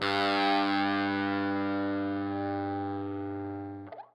SPOOKY    AC.wav